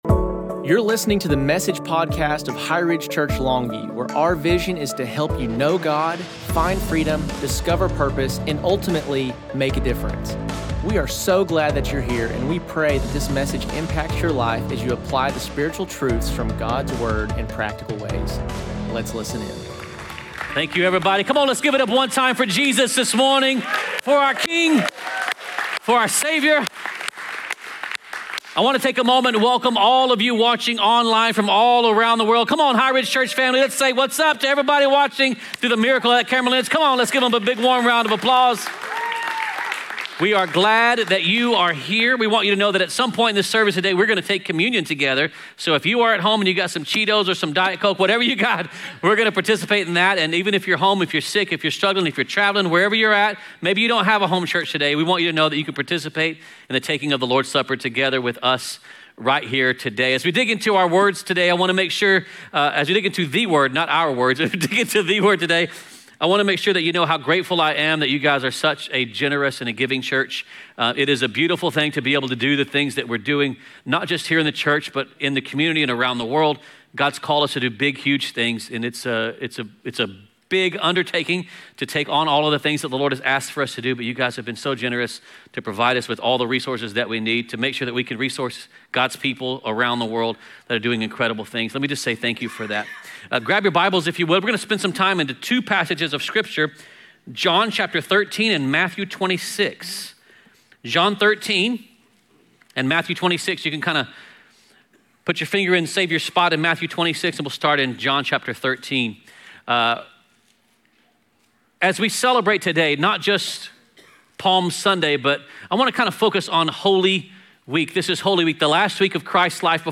2025 Message